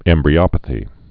(ĕmbrē-ŏpə-thē)